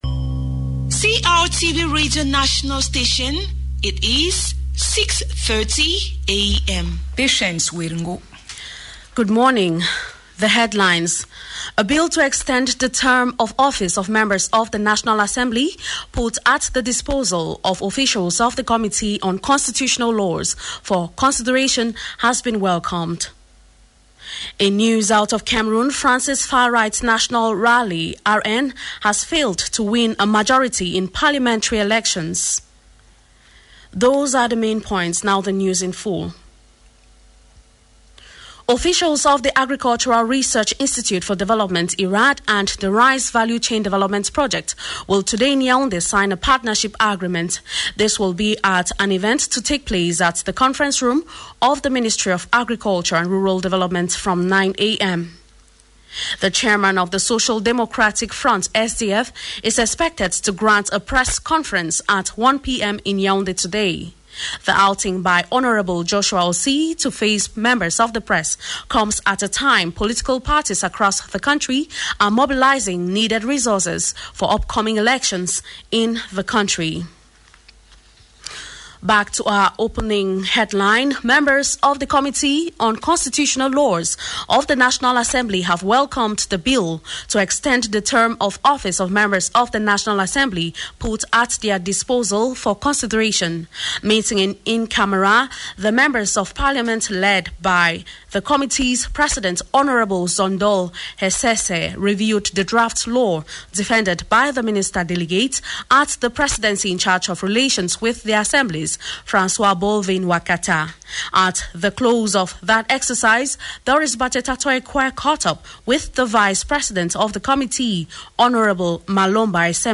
The 6:30am News of July 8,2024 - CRTV - Votre portail sur le Cameroun